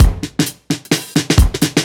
OTG_Kit 5_HeavySwing_130-B.wav